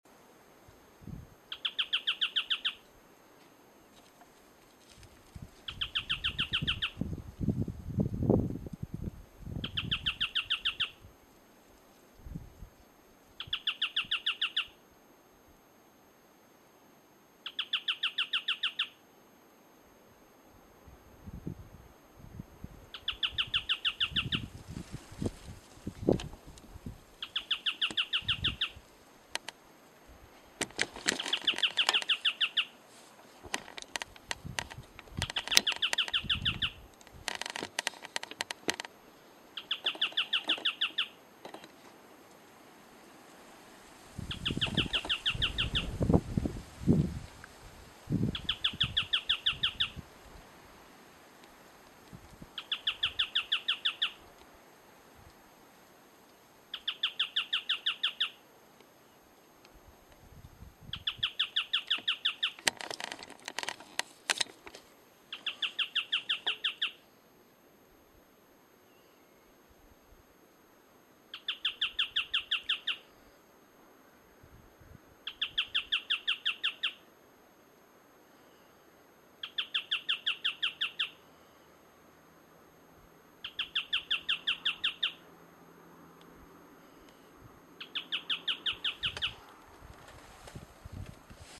Eugralla paradoxa
Nome em Inglês: Ochre-flanked Tapaculo
Fase da vida: Adulto
Localidade ou área protegida: Cañadón de la Mosca
Condição: Selvagem
Certeza: Gravado Vocal